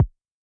RDM_Raw_SY1-Kick04.wav